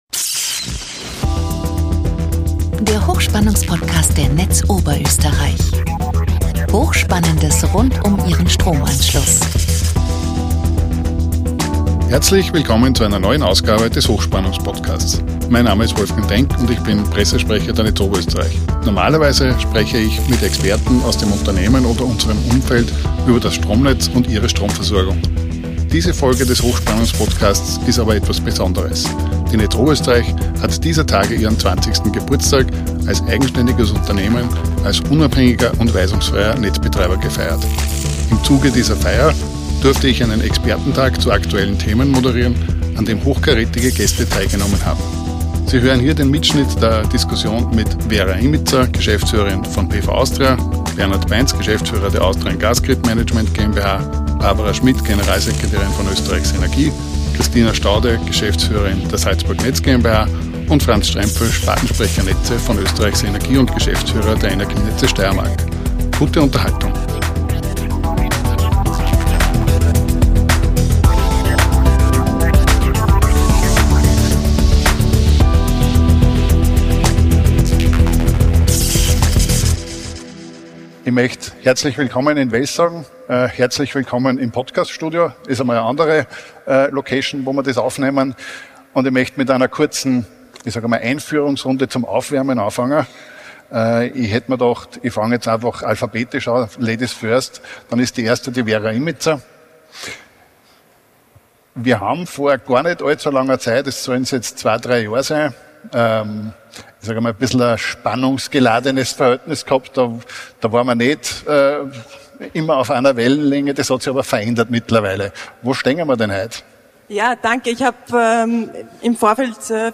Diese Folge des Hochspannungspodcasts ist etwas Besonderes: Die Netz Oberösterreich hat dieser Tage ihren 20. Geburtstag als unabhängiger und weisungsfreier Netzbetreiber gefeiert. Im Zuge dieser Feier gab es einen Expertentalk zu aktuellen Themen, dessen Mitschnitt Sie hier nachhören können.